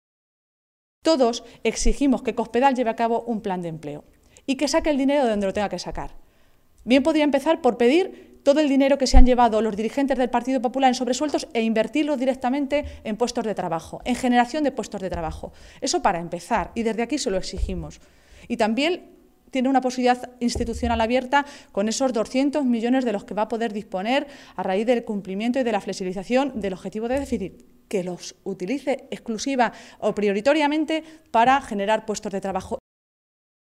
Maestre se pronunciaba de esta manera en una comparecencia ante los medios de comunicación, en mitad de la celebración del Comité Regional del PSOE castellano-manchego, que coincidía con un mitin organizado al mismo tiempo por el PP en Toledo con motivo del segundo aniversario de la llegada al poder de Cospedal.
Cortes de audio de la rueda de prensa